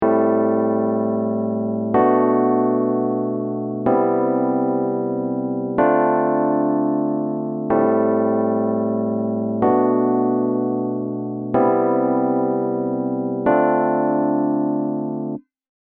これにリバースエフェクトをかけてみると….。
ちょっと不思議なモジュレーションのような効果が得られますね。